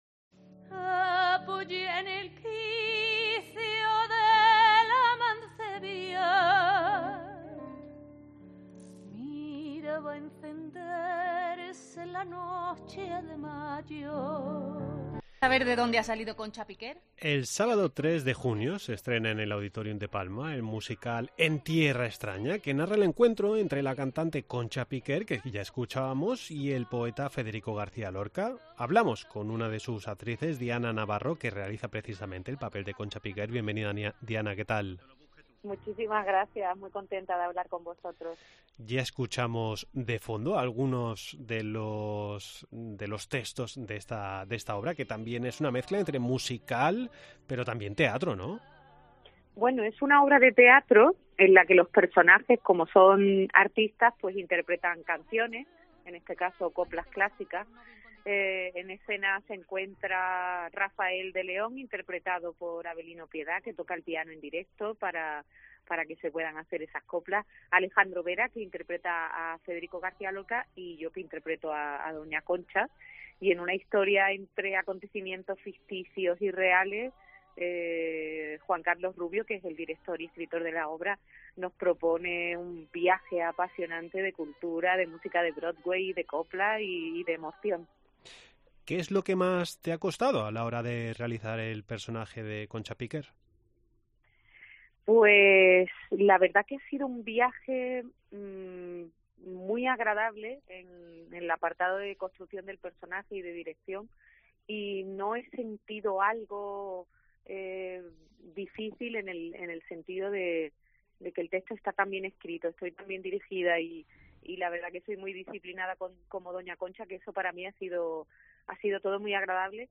El 3 de junio a las 20h y el domingo 4 a las 18h, en el Auditorium de Palma, se representa la obra "En Tierra Extraña". Hablamos con Diana Navarro quien interpreta a Chonca Piquer